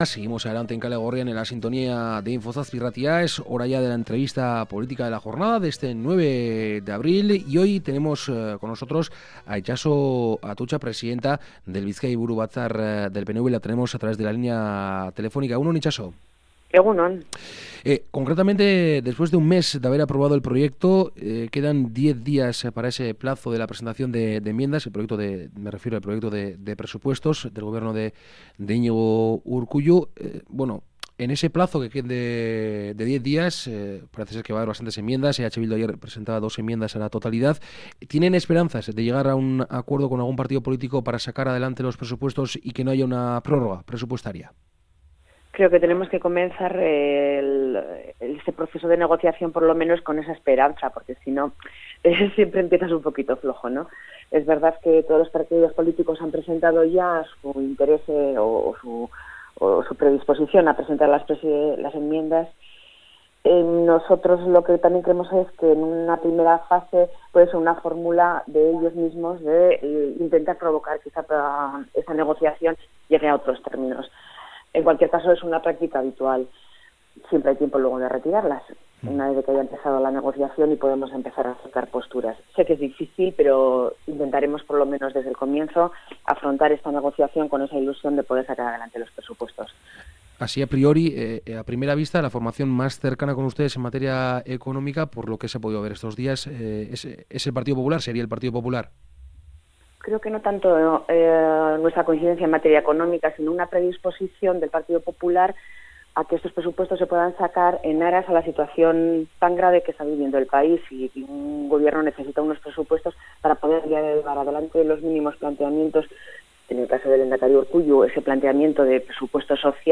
Entrevistamos a la presidenta del Bizkai Buru Batzar de EAJ-PNV, Itxaso Atutxa.
Itxaso Atutxa (PNV) Iruzkinik ez Idazlea: Kolaborazioa Kategoria: Entrevista política Entrevistamos a la presidenta del Bizkai Buru Batzar de EAJ-PNV, Itxaso Atutxa.